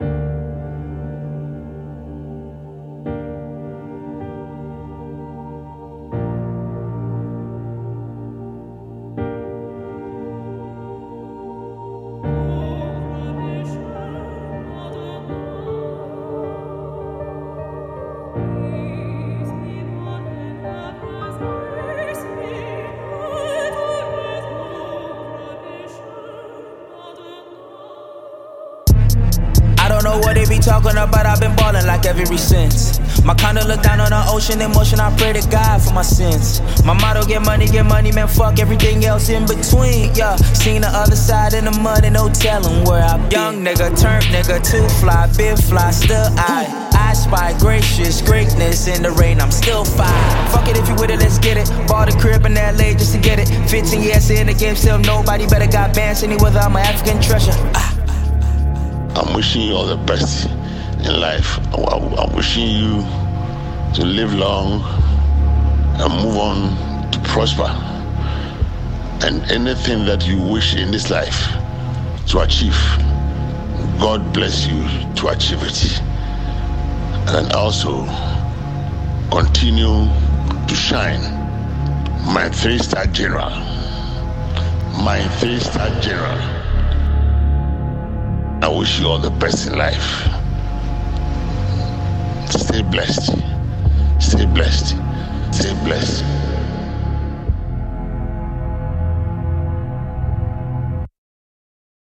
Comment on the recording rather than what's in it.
studio album